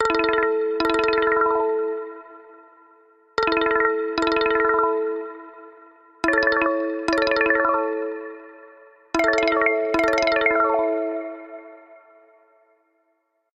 • Качество: 128, Stereo
трель
Хороший и самый обычный звук на СМС